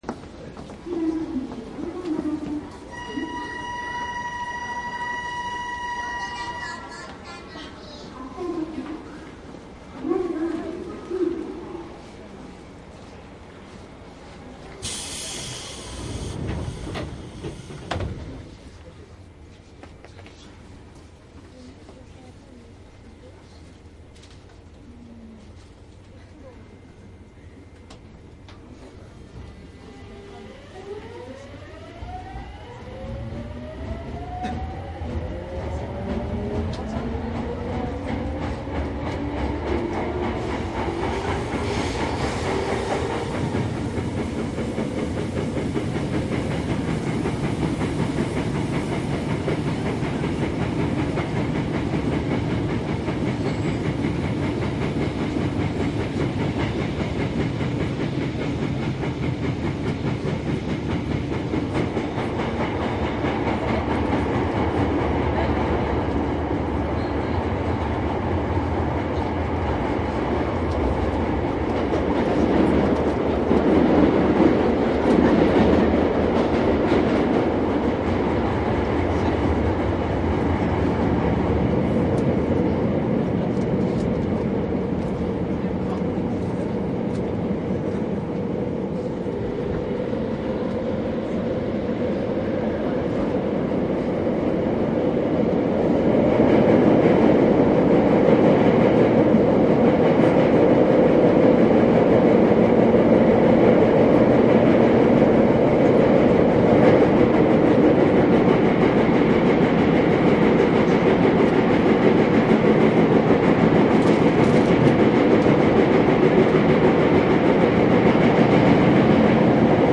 ☆☆鉄道走行音CD☆☆JR東日本207系900番台・JR常磐線・営団千代田線（各停）代々木上原ゆき
JR常磐緩行線・営団地下鉄千代田線（各停）我孫子→代々木上原
収録車両： モハ20６-903
サンプル音声 モハ206-903.mp3
マスター音源はデジタル44.1kHz16ビット（マイクＥＣＭ959）で、これを編集ソフトでＣＤに焼いたものです。